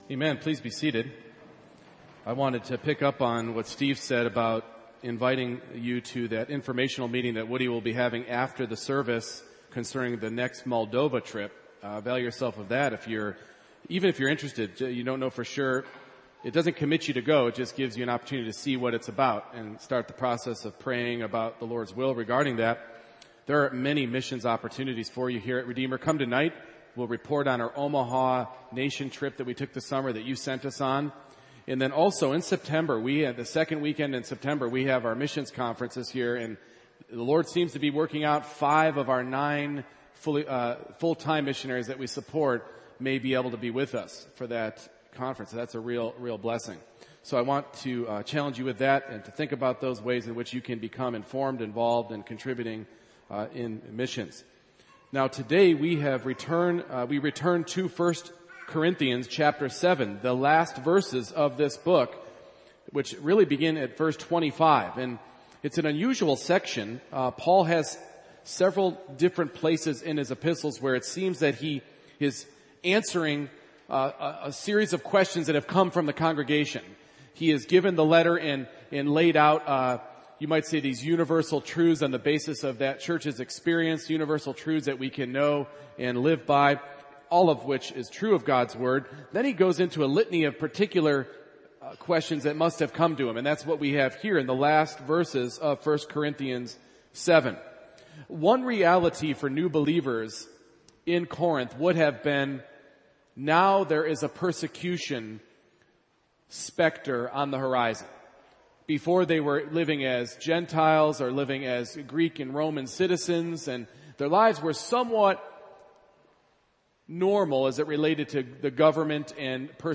1 Corinthians 7:25-40 Service Type: Morning Worship I. Be careful making big decisions in perilous times II.